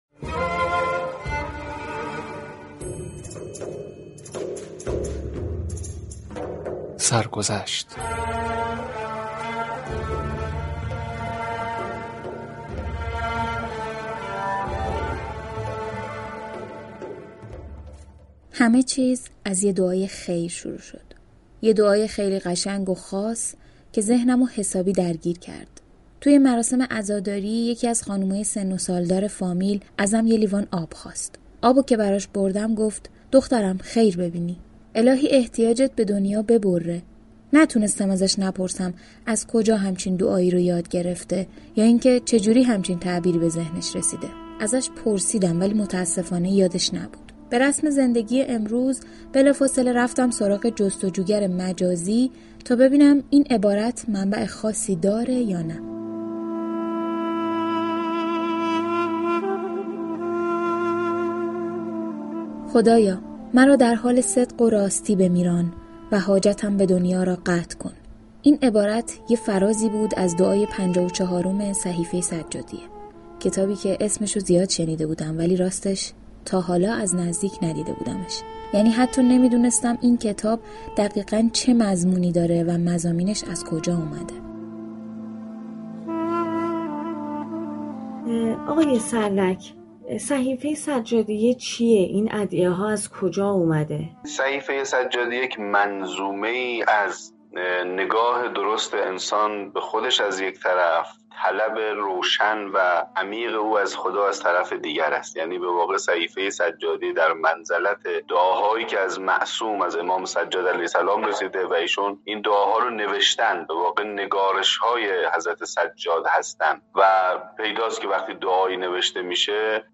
به گزارش روابط عمومی رادیوصبا، ویژه برنامه «سرگذشت» عنوان یكی از ویژه برنامه هایی است كه در ایام شهادت اهل بیت تقدیم مخاطبان می شود، این برنامه در قالب مستند با شعار « نام آوران دین و معنویت را بیشتر بشناسیم» شنوندگان را با بزرگان دین آشنا می كند.